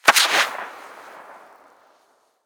Incendiary_Far_03.ogg